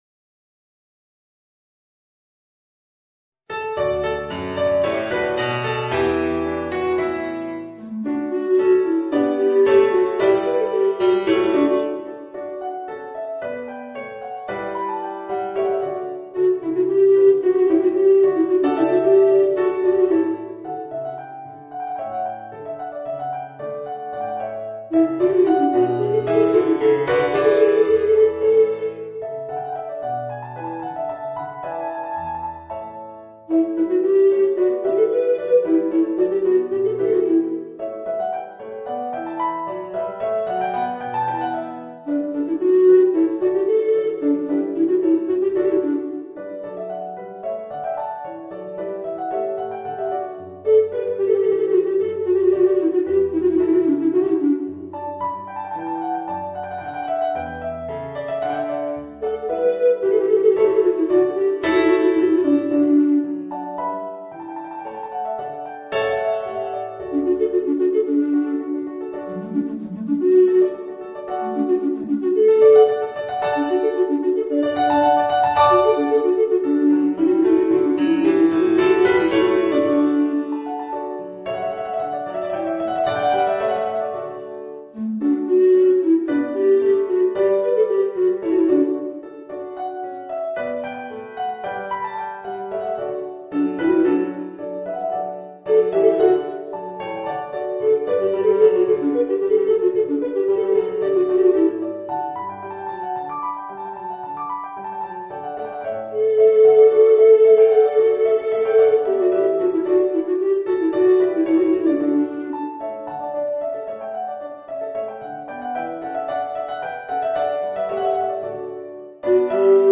Voicing: Bass Recorder and Organ